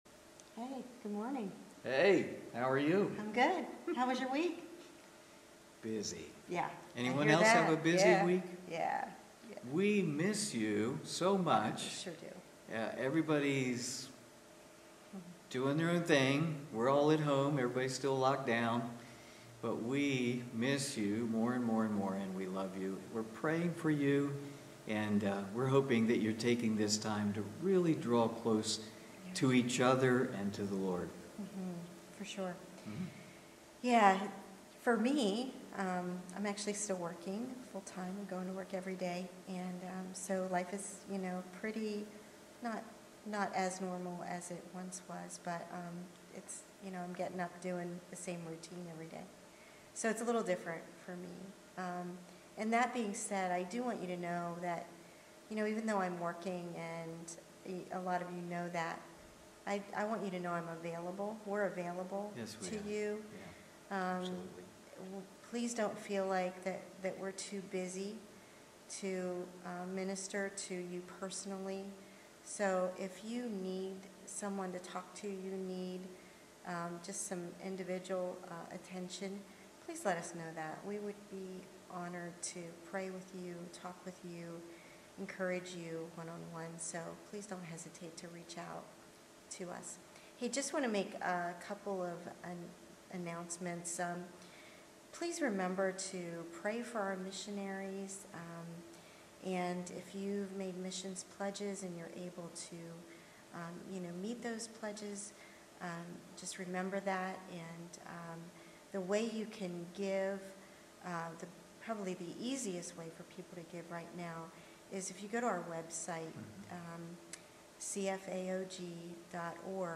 Sunday morning online service